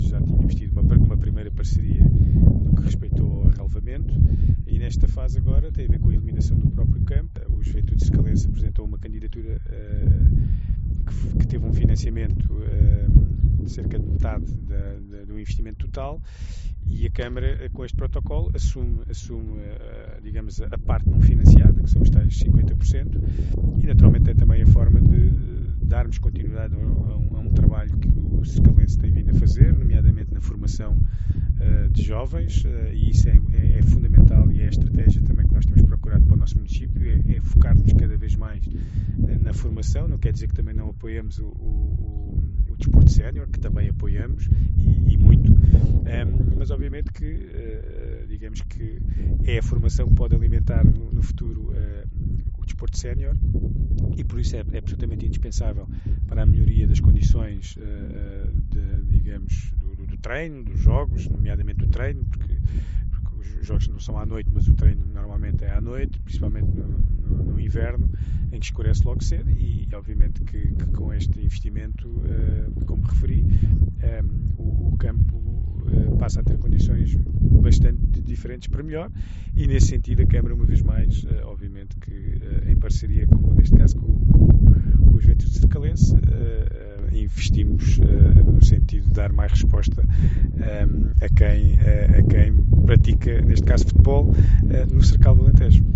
Declarações do Presidente da Câmara Municipal de Santiago do Cacém, Álvaro Beijinha